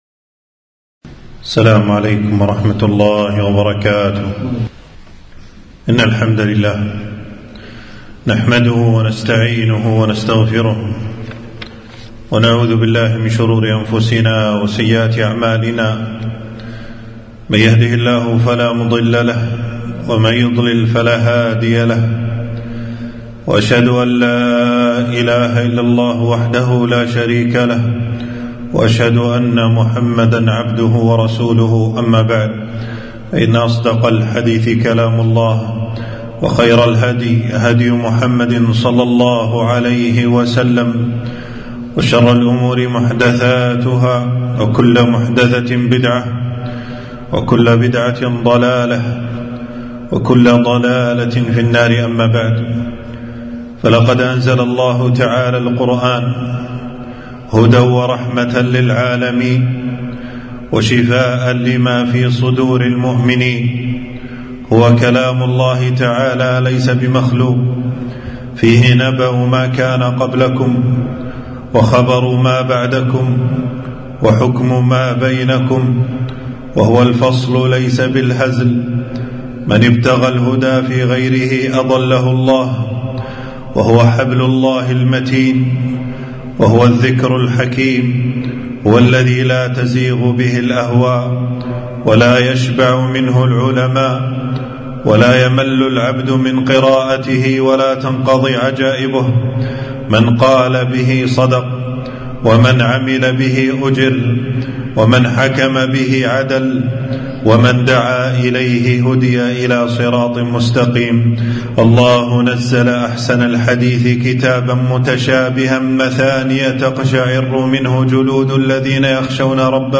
خطبة - ورتل القرآن ترتيلا - دروس الكويت